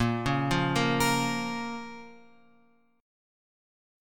A# Minor